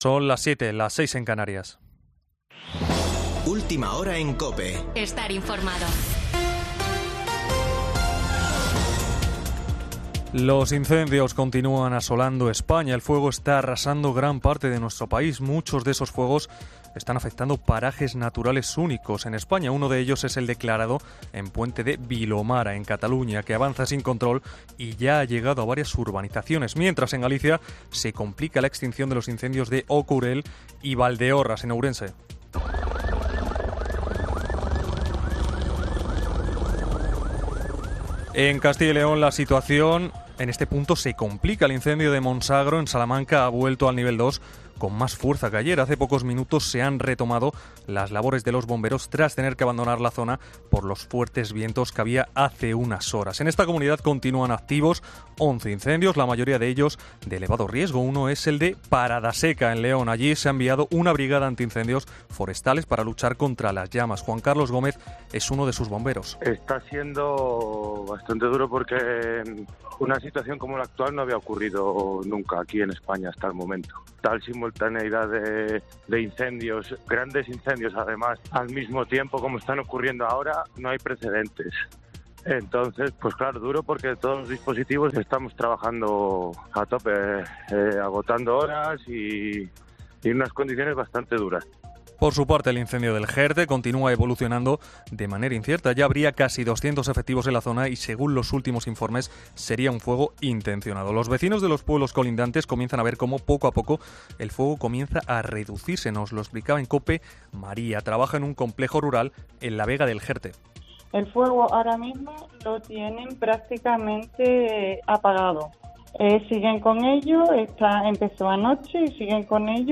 Boletín de noticias de COPE del 17 de julio de 2022 a las 19:00 horas